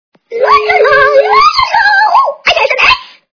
- звуки для СМС
Звук Голос - Ла ла ла СМС